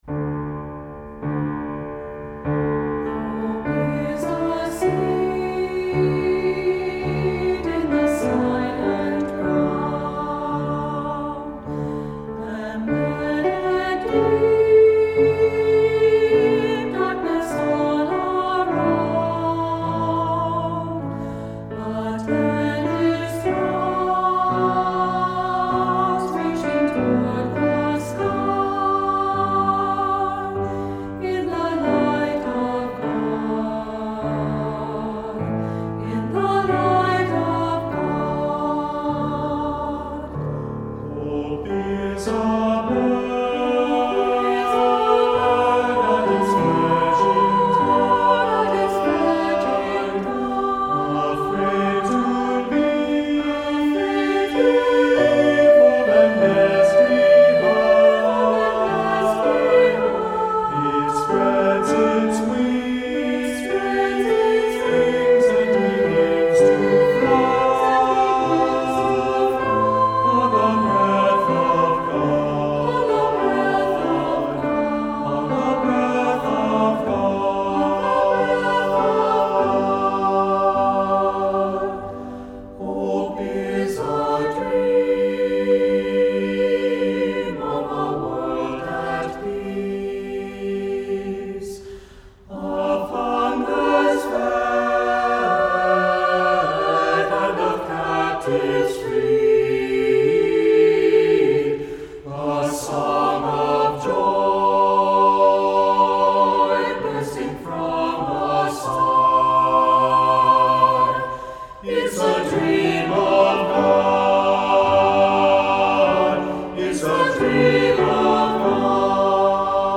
Voicing: SAB